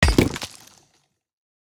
axe-mining-stone-4.ogg